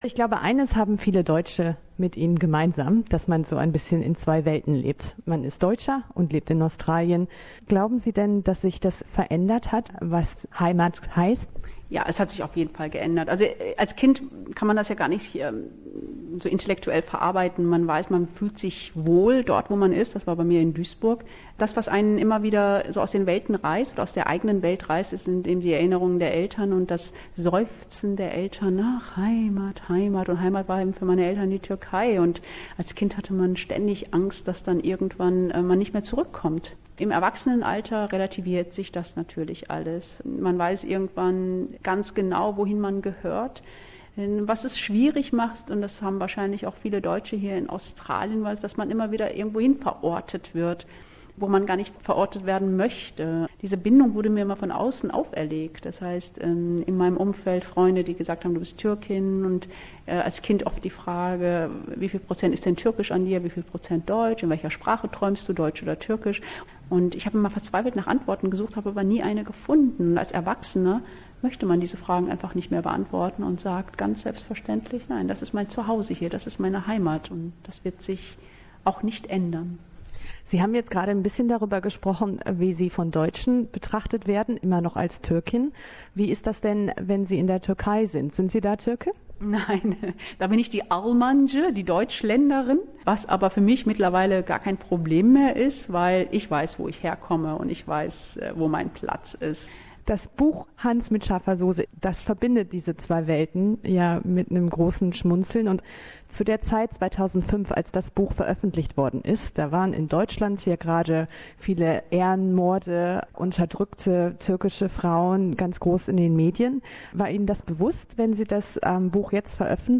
In dem Gespräch geht es vorrangig um das Thema Migration und Heimat. Dabei gibt es viele Ähnlichkeiten zwischen der türkischen Migrationsgeschichte in Deutschland, und der Situation der Deutschen hier in Australien.